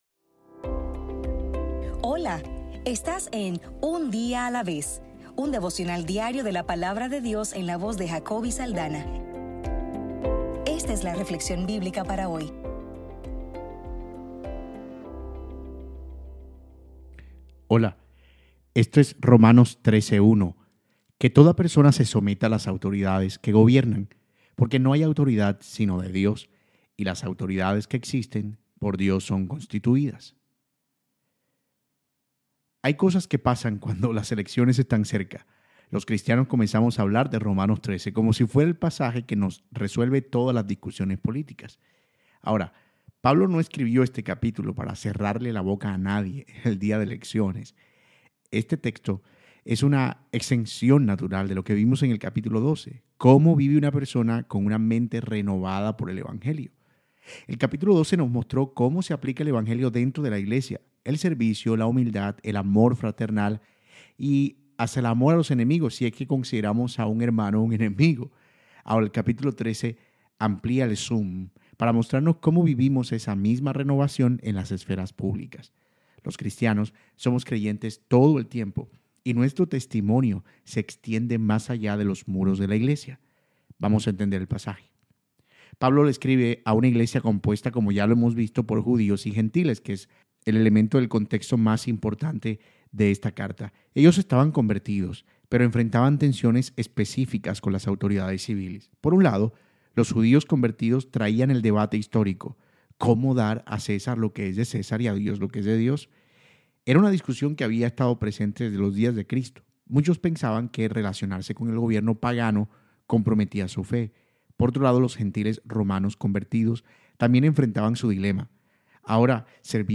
Devocional para el 23 de agosto